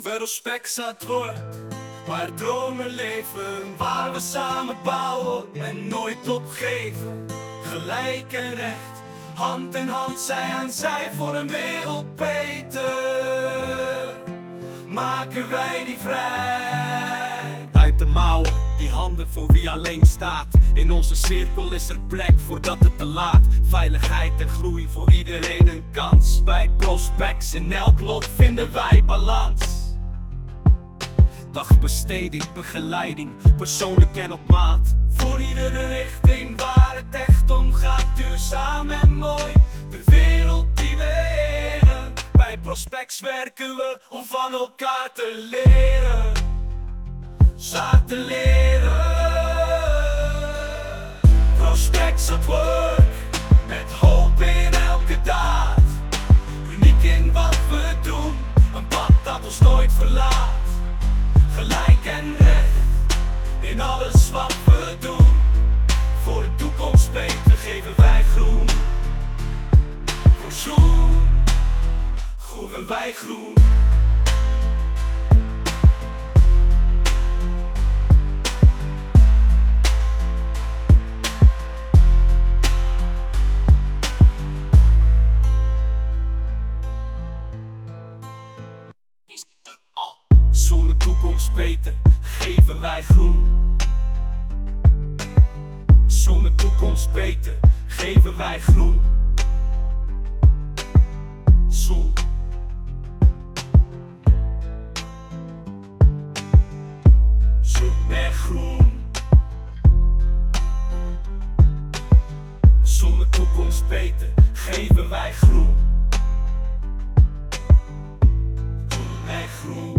Een van onze klanten heeft de waarden die wij uitdragen als bedrijf samengevat in een nummer. Dit nummer is gemaakt met AI (artificiële intelligentie).